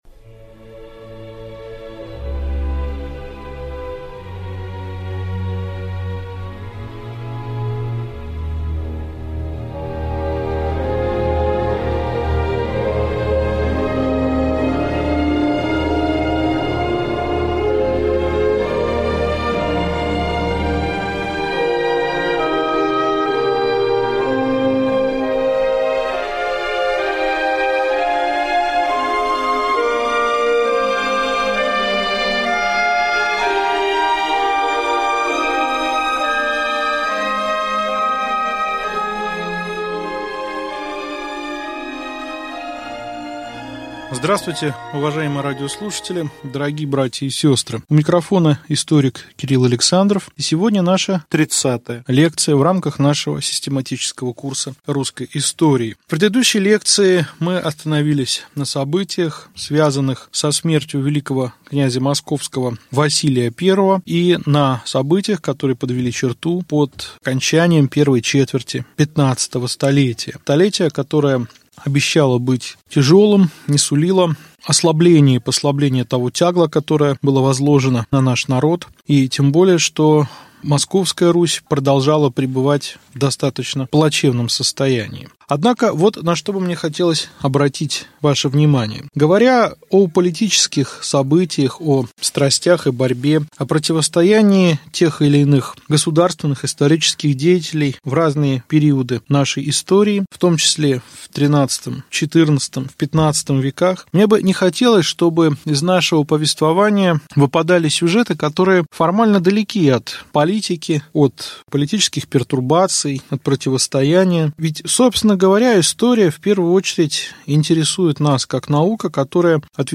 Аудиокнига Лекция 30. Русский быт XV – начала XVI вв | Библиотека аудиокниг